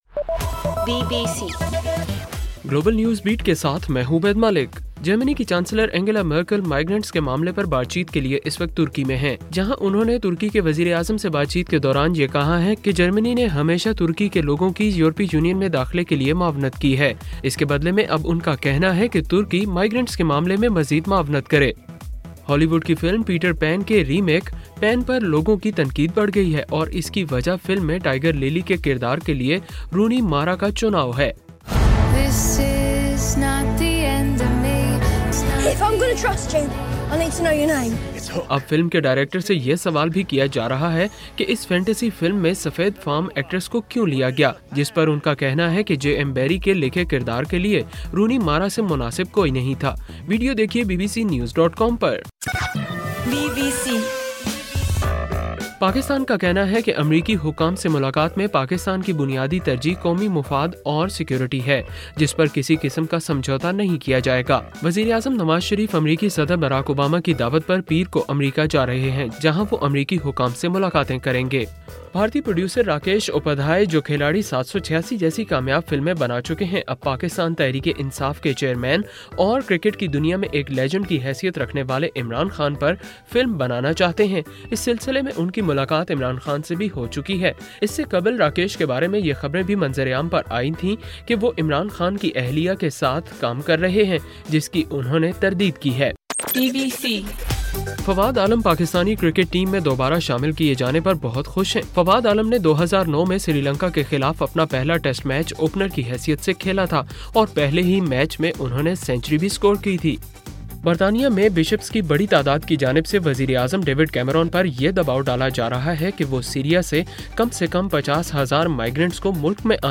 اکتوبر 18: رات 8 بجے کا گلوبل نیوز بیٹ بُلیٹن